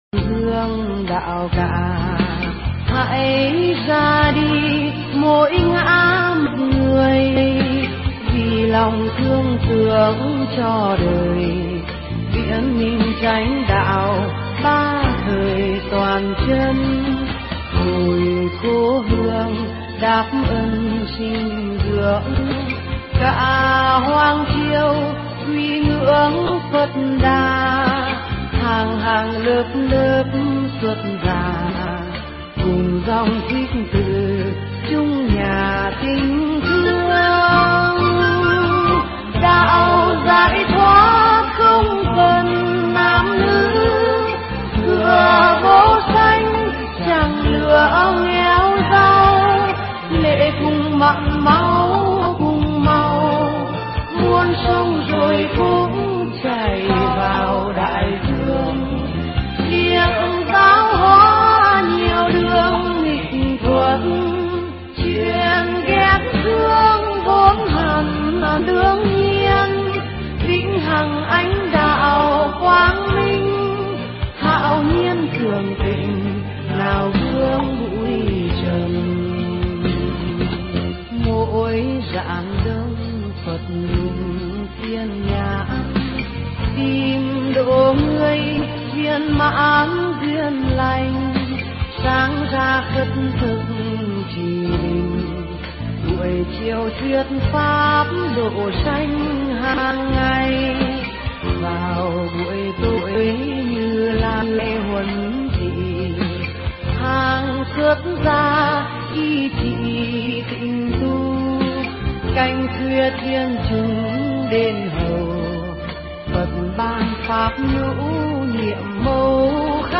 Nghe Mp3 thuyết pháp Đừng Phung Phí Hơi Thở
Pháp âm Đừng Phung Phí Hơi Thở